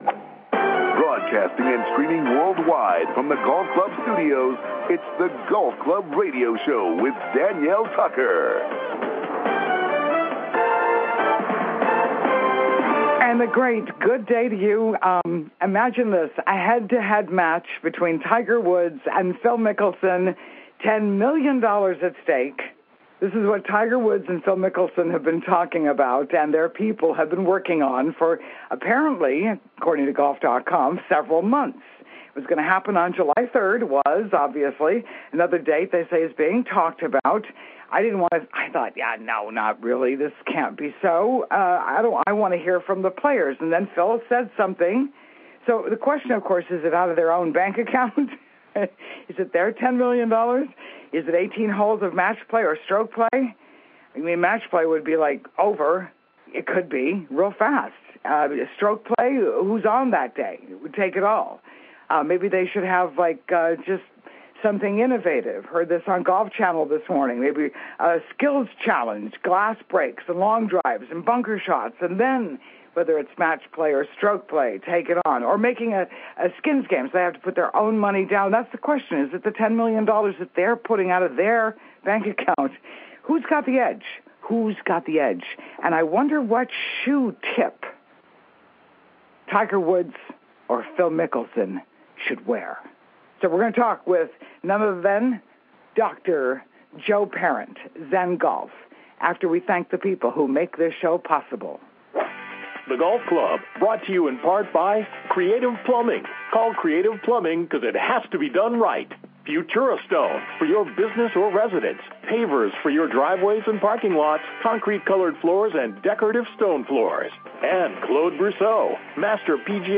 Live SATURDAY MORNINGS: 7:00 AM - 8:30 AM HST